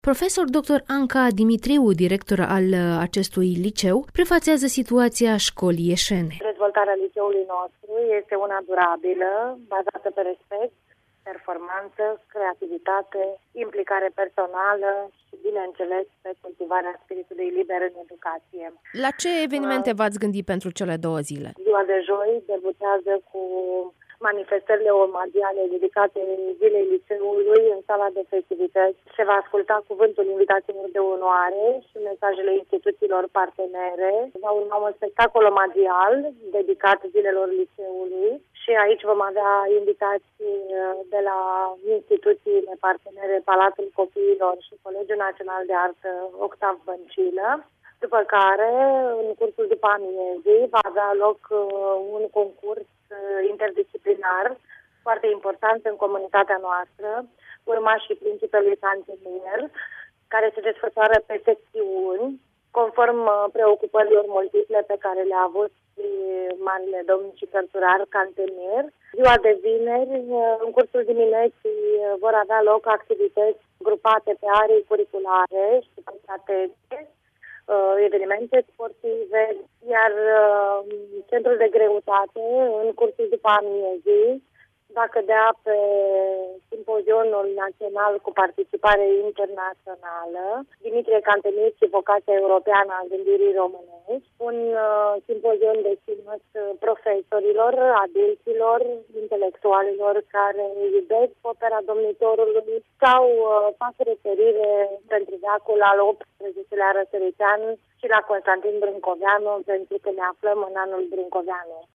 (INTERVIU)ZILELE LICEUL TEORETIC “DIMITRIE CANTEMIR” IAŞI